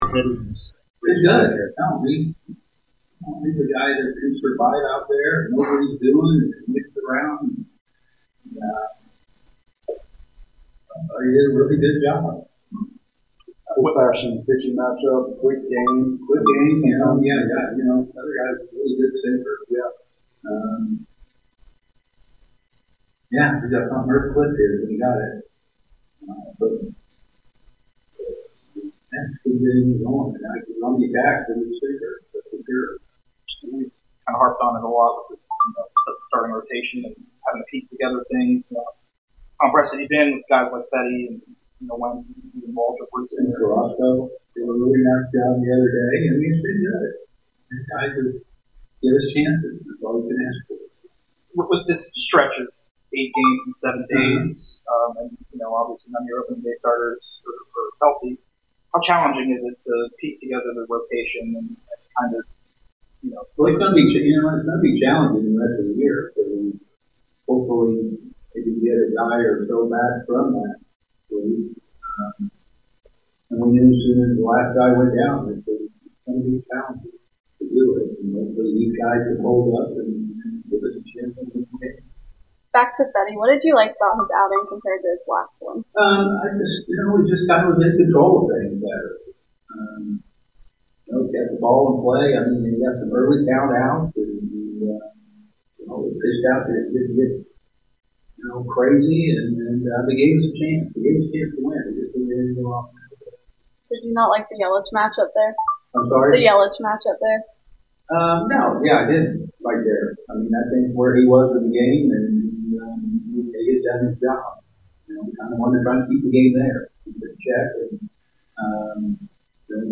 Atlanta Braves Manager Brian Snitker Postgame Interview after losing to the Milwaukee Brewers at Truist Park.